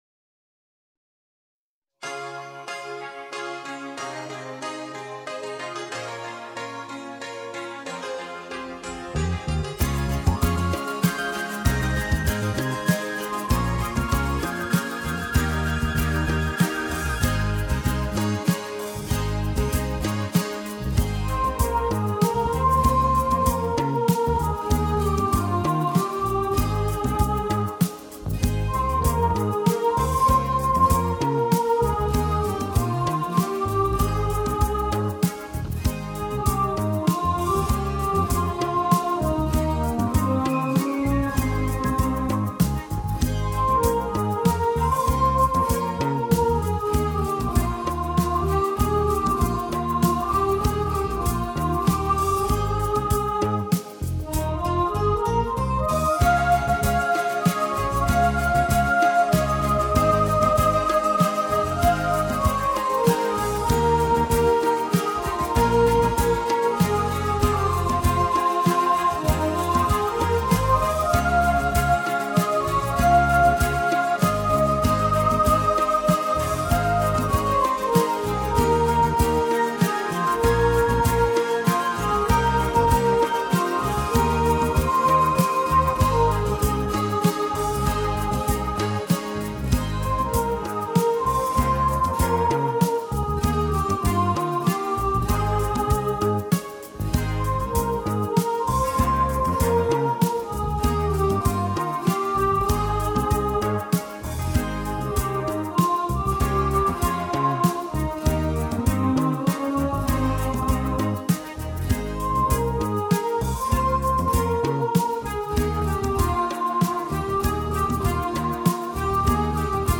"Move On Soprano".
Move-On-Soprano.mp3